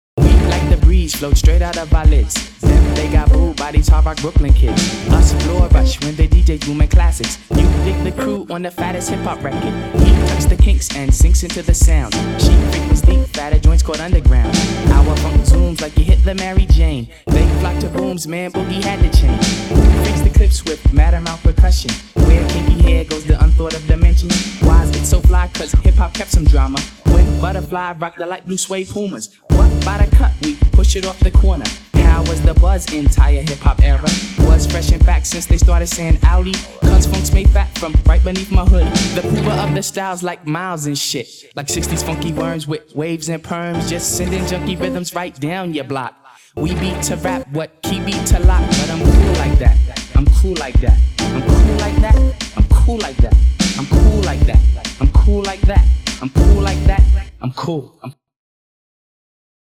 In Montreal. Digitakt here.